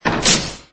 pickaxe.ogg